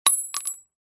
Звуки падения гильзы
Звук упавшей на бетон отстрелянной гильзы